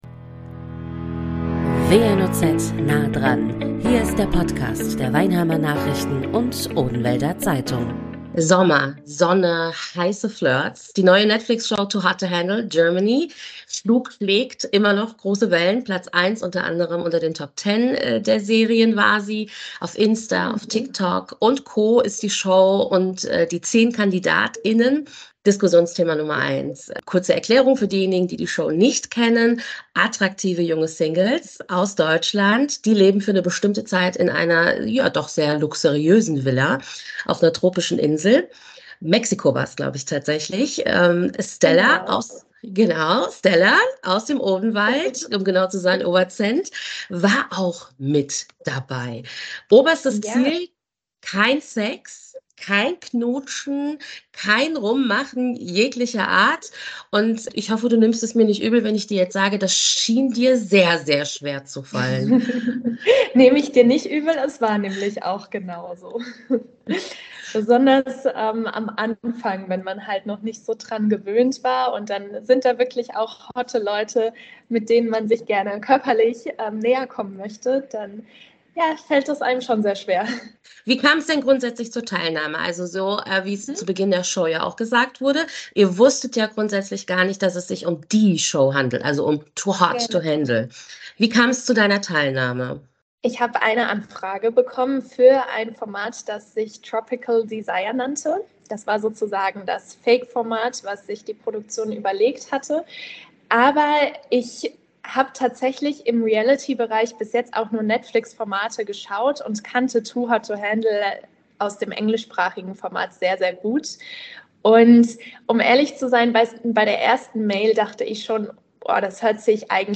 Studiogast